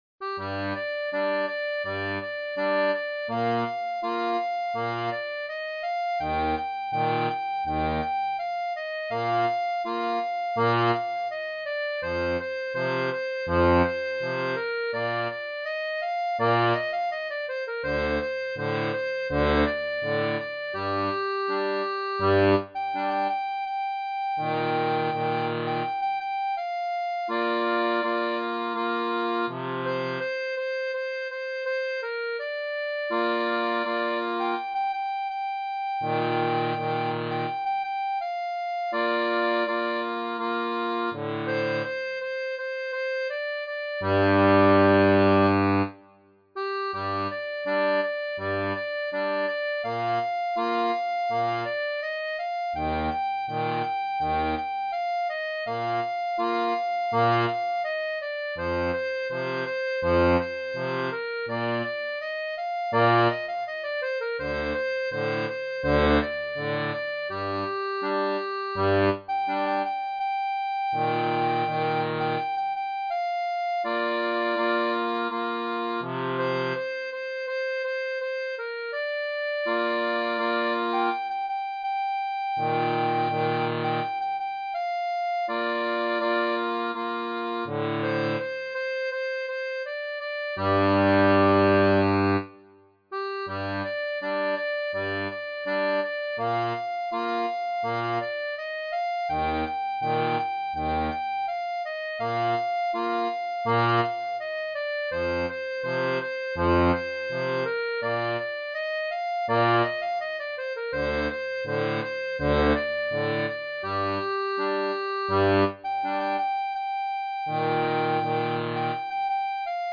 • Une tablature pour diato 3 rangs (Sol-Do-Alt) en Sol
Chanson française